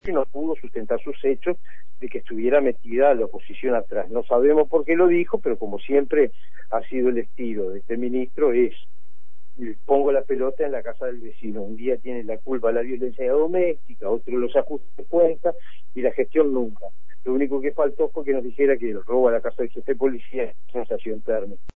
El diputado agregó que el pedido de pasar a una "sesión secreta" fue totalmente "irrelevante".